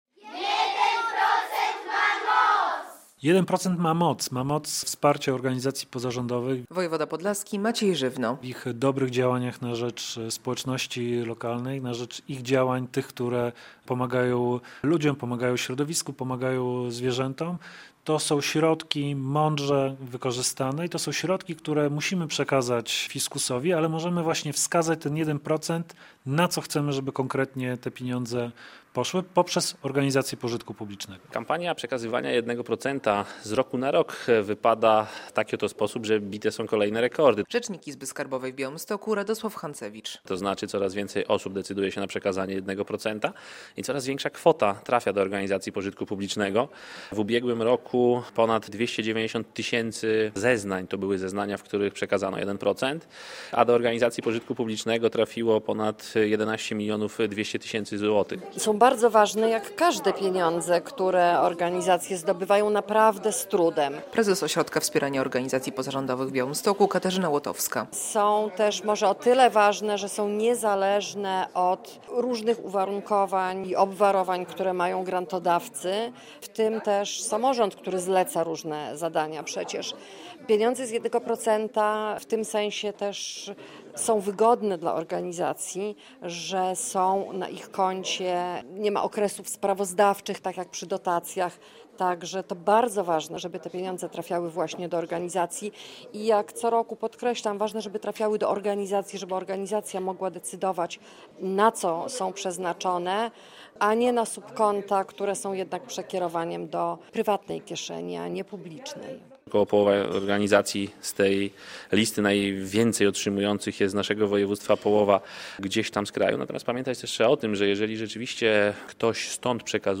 relacja
W tym roku akcja odbywa się pod hasłem "1 % ma moc" - mówi wojewoda podlaski Maciej Żywno.